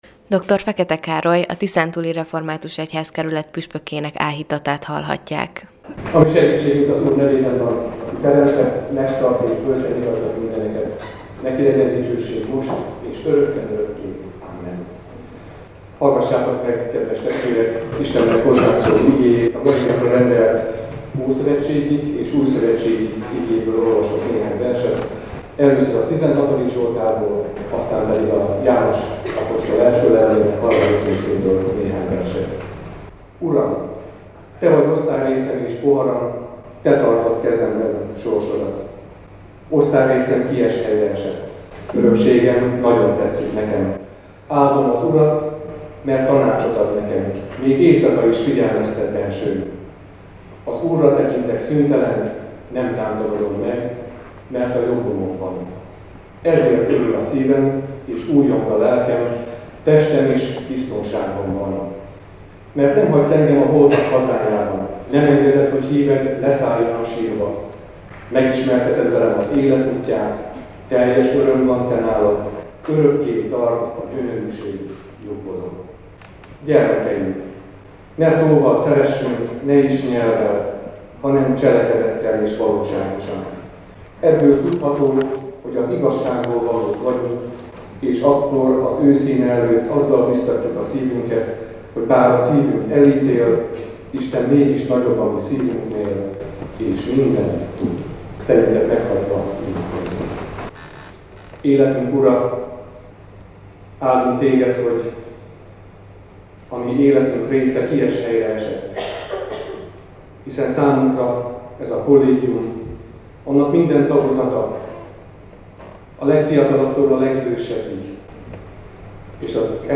Az Európa Rádió összeállítása az eseményről: